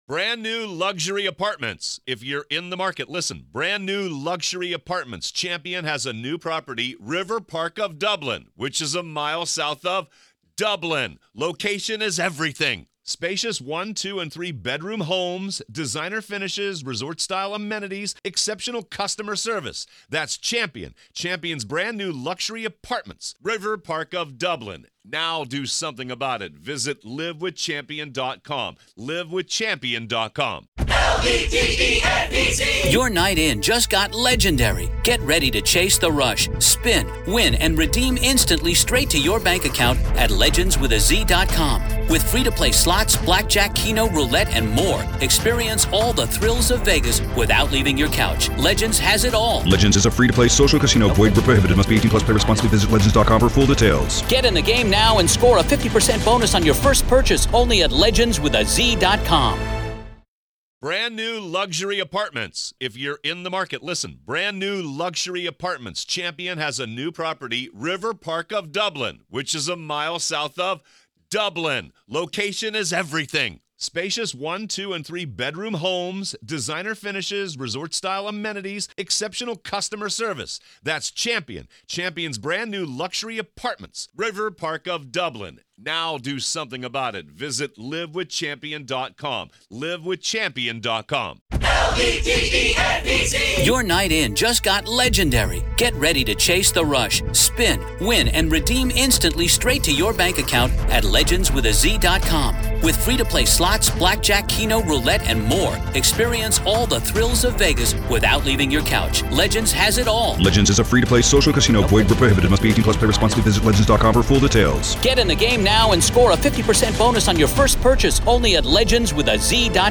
Full Interview With Suspected Gilgo Beach Killer Rex Heuermann